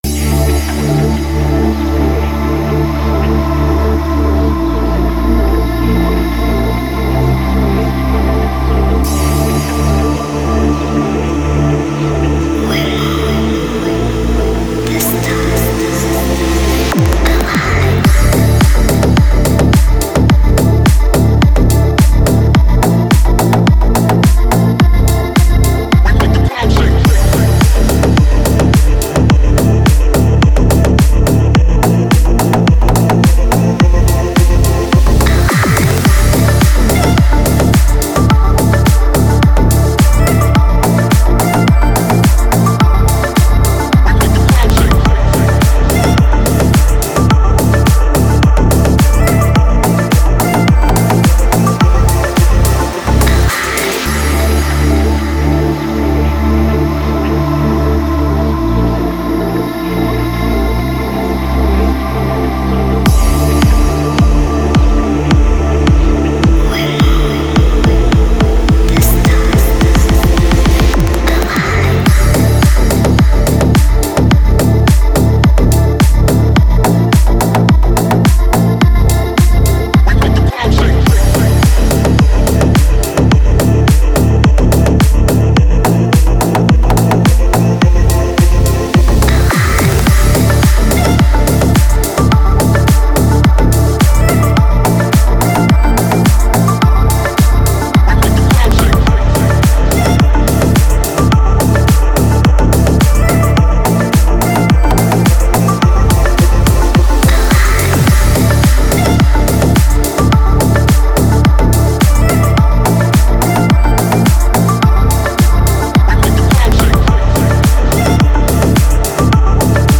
Хорошие клубные треки
Клубная музыка